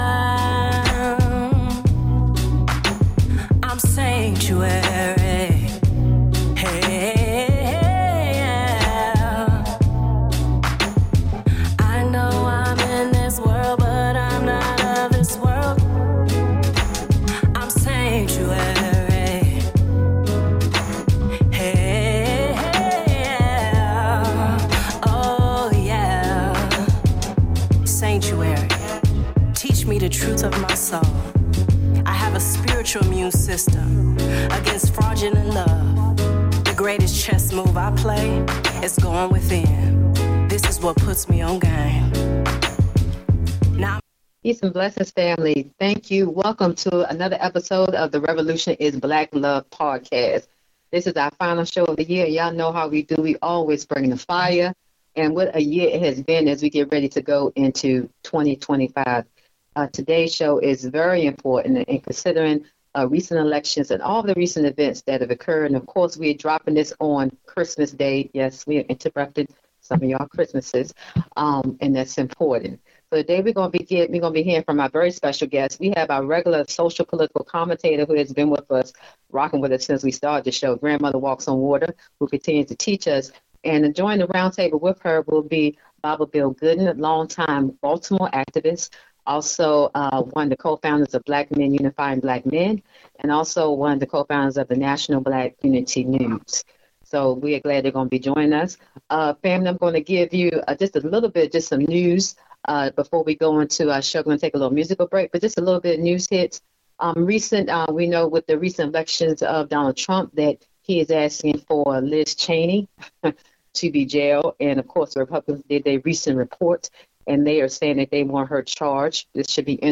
Audio and Radio Broadcasts